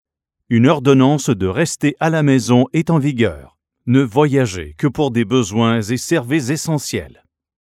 With ten COVID-19 specific pre-recorded messages, practicing social distancing just got easier.
Unlike standard siren systems, Pathfinder projects a full, clear public address that is easy to understand.
Siren Tones and Voice Messages